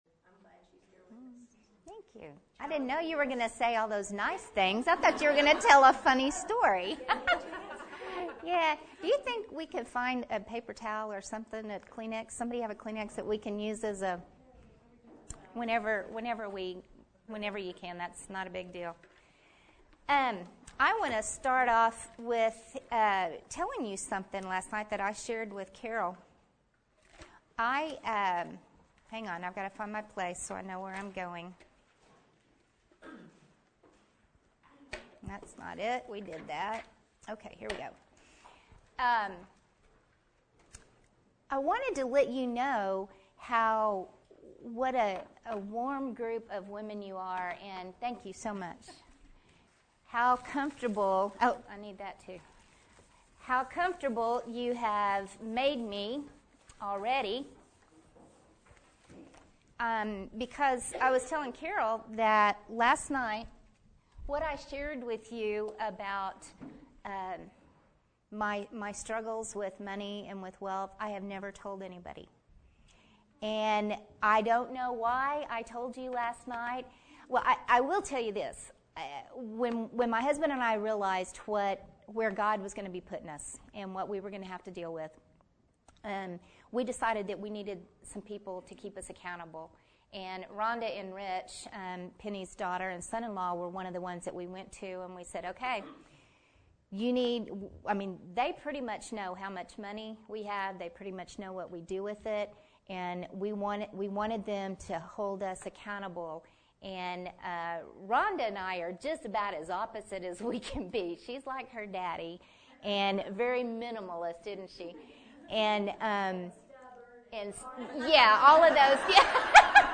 Ladies Retreat - 2007
Sermons